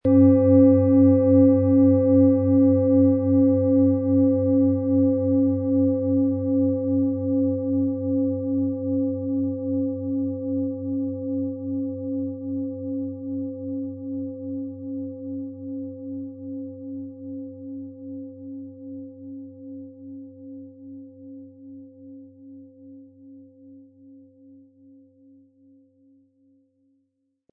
OM Ton
Es ist eine von Hand gearbeitete tibetanische Planetenschale OM-Ton.
• Tiefster Ton: Jupiter
• Höchster Ton: DNA
PlanetentöneOM Ton & Jupiter & DNA (Höchster Ton)
MaterialBronze